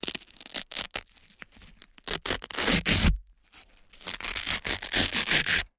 Velcro is pulled apart at various speeds. Tear, Velcro Rip, Velcro